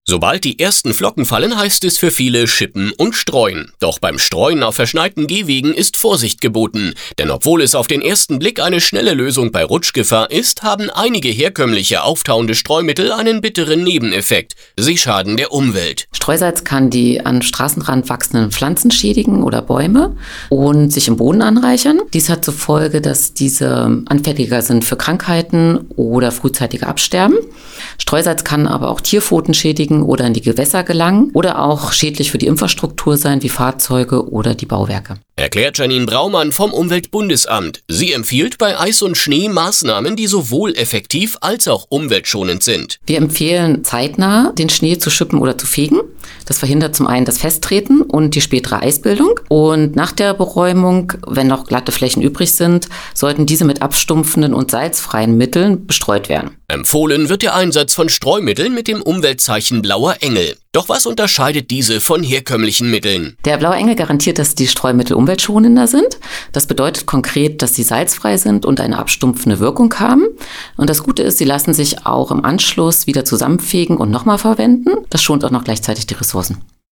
Radiobeiträge
BE-Hörbeitrag-Streumittel-bei-Glätte.mp3